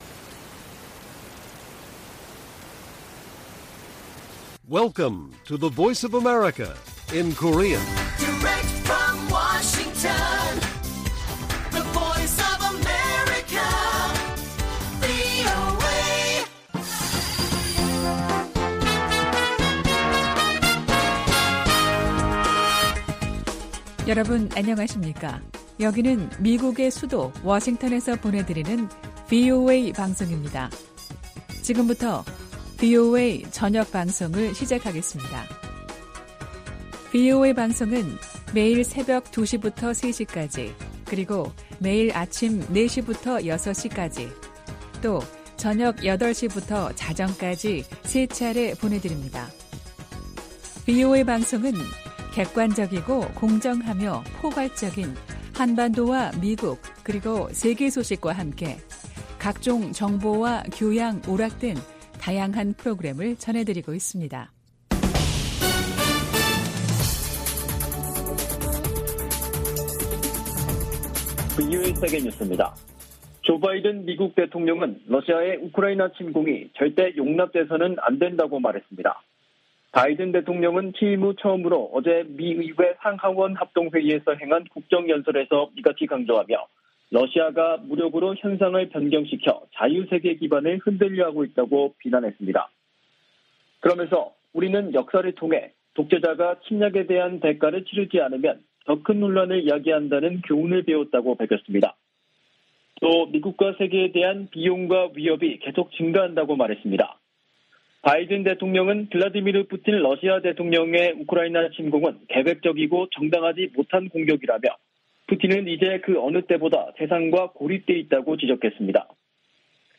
VOA 한국어 간판 뉴스 프로그램 '뉴스 투데이', 2022년 3월 2일 1부 방송입니다. 조 바이든 미국 대통령이 취임 후 첫 국정연설에서 러시아의 우크라이나 침공을 강력 비판했습니다.